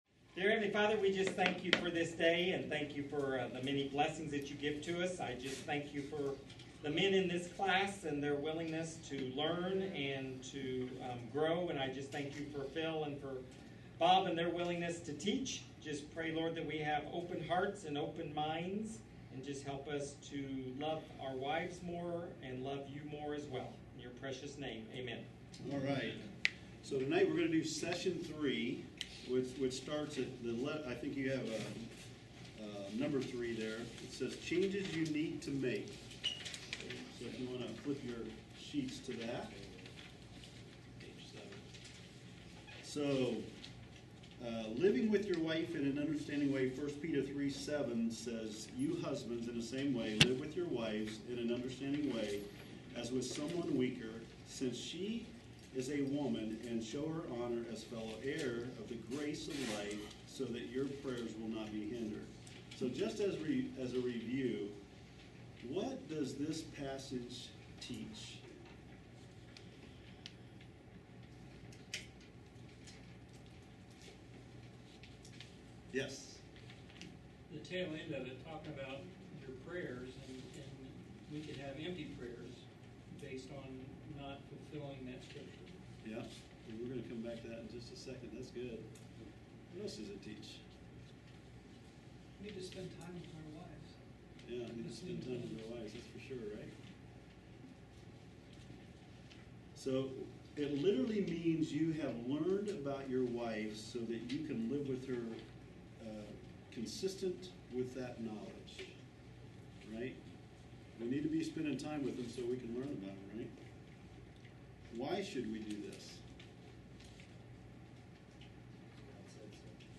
"Loving Your Wife" teaches you how to analyze and critique how your wife sees your love toward her being demonstrated. Helpful discussions, interaction with the teacher/class, and clear instruction from the Word of God challenges you to understand your wife.